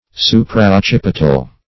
Supraoccipital \Su`pra*oc*cip"i*tal\, a. (Anat.)